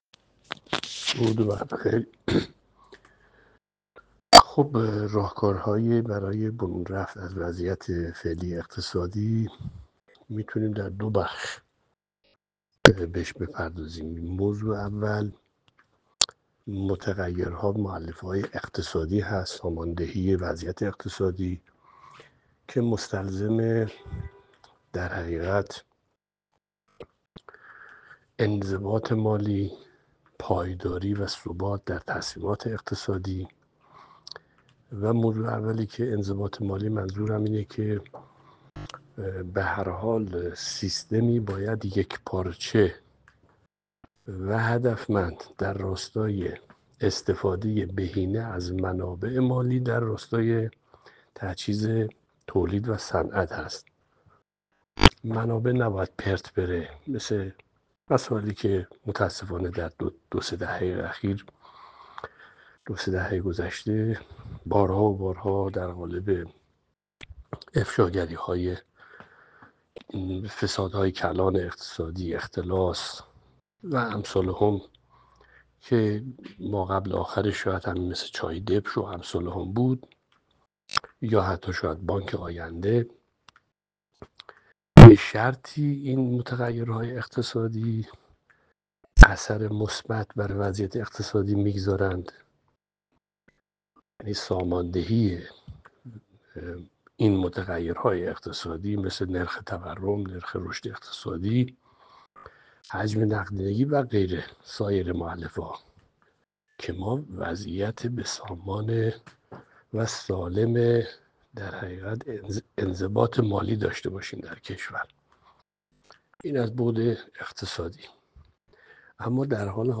کارشناس اقتصادی در گفتگو با بازار عنوان کرد؛